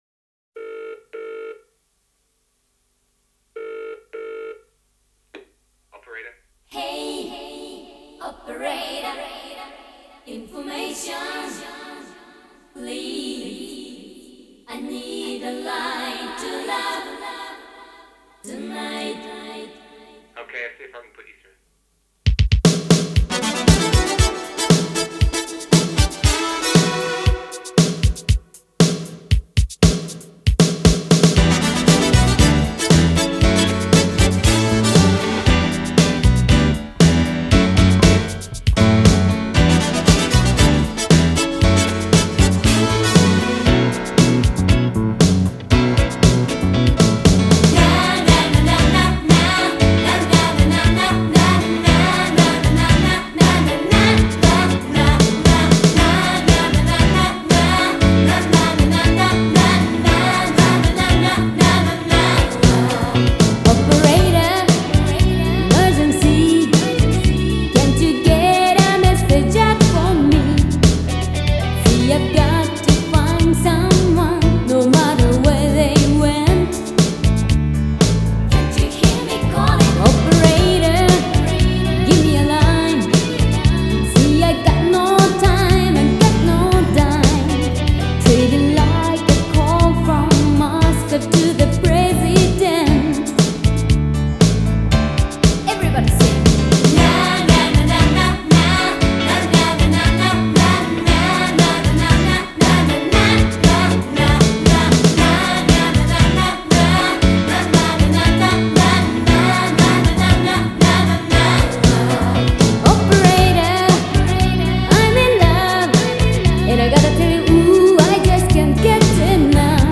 Обладает красивым и проникновенным высоким голосом.
Жанр: J-Pop, Ballad